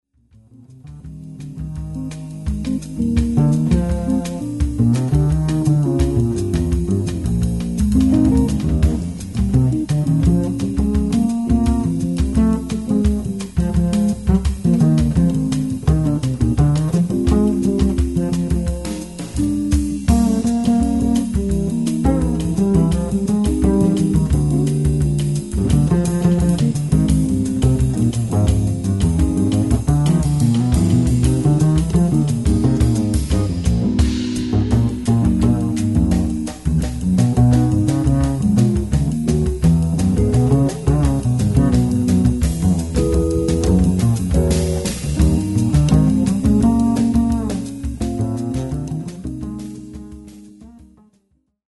Hør udpluk fra bassolo.